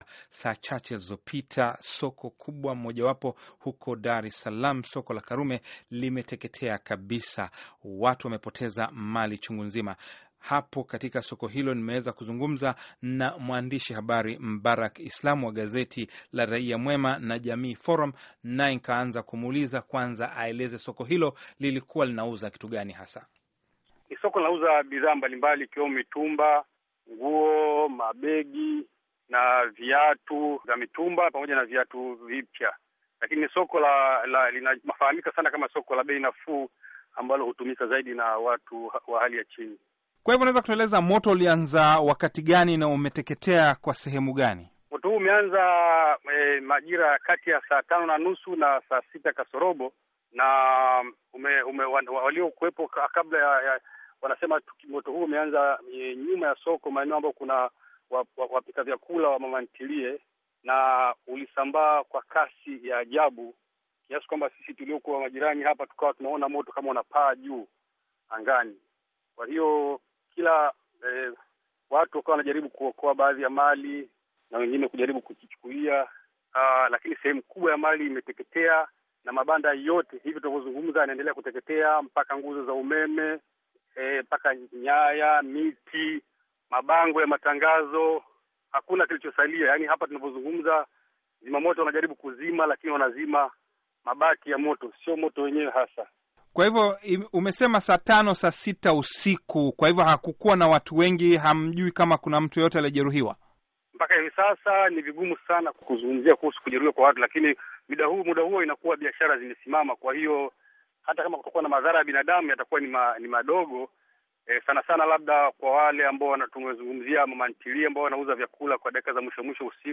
Mahojiano na waloshuhudia kuteketea kwa soko la Karume